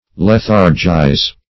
Search Result for " lethargize" : The Collaborative International Dictionary of English v.0.48: Lethargize \Leth"ar*gize\ (l[e^]th"[.a]r*j[imac]z), v. t. [imp.